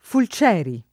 vai all'elenco alfabetico delle voci ingrandisci il carattere 100% rimpicciolisci il carattere stampa invia tramite posta elettronica codividi su Facebook Fulcieri [ ful ©$ ri ] o Folcieri [ fol ©$ ri ] pers. m. stor. e cogn.